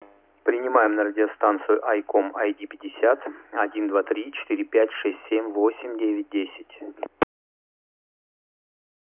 Пример записи приема на карту SD (128 КБ/сек) в аналоговом режиме без обработки: